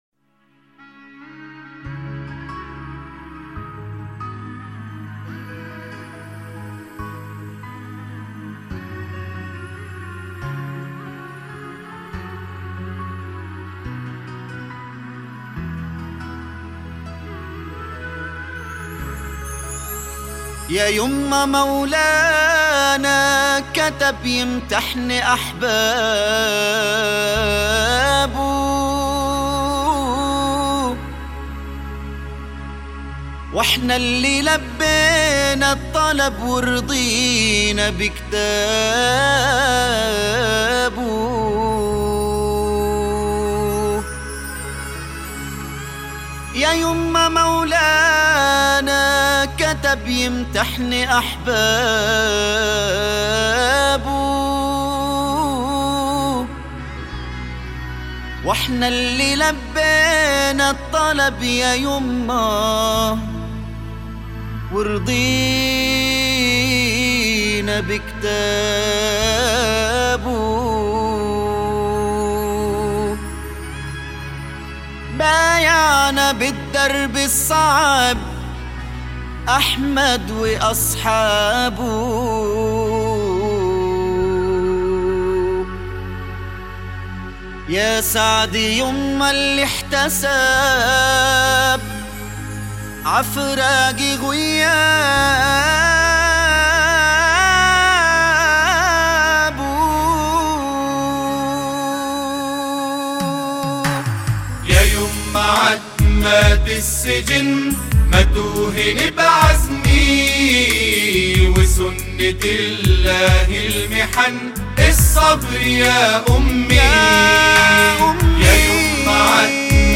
أناشيد فلسطينية... عتمات السجن البث المباشر الرئيسية الأخبار البرامج فلسطين الكوثر + فارسی البث المباشر بحث فارسی شاركوا هذا الخبر الإثنين 9 أكتوبر 2017 - 14:34 بتوقيت غرينتش أناشيد فلسطينية...